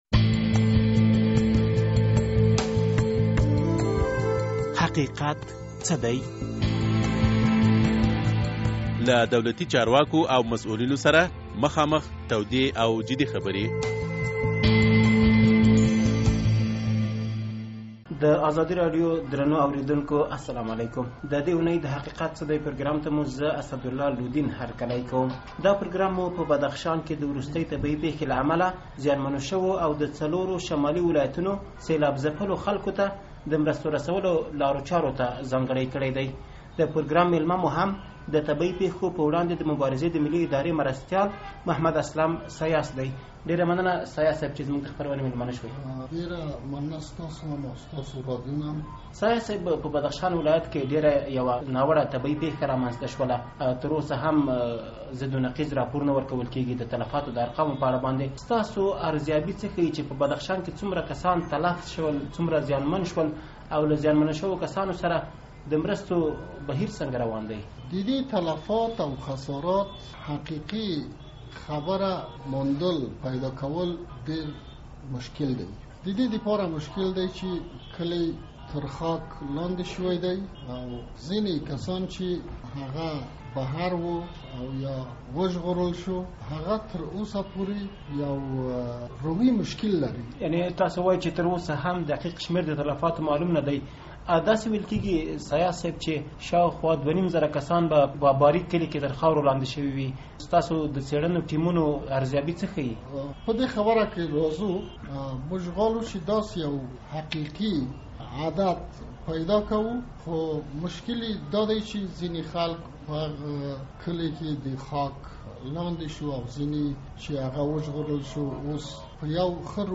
د حقیقت څه دی، په دې پروګرام کې مو د افغانستان د طبیعي پیښو په وړاندې د مبارزې د ملي ادارې له مرستیال محمد اسلم سیاس سره د بدخشان د مصیبت ځپلو او د شمالي ولایتونو د سیلاب ځپلو خلکو سره د مرستو په لارو چارو خبرې کړي.